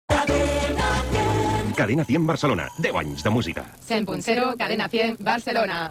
Indicatiu dels 10 anys de l'emissora a Barcelona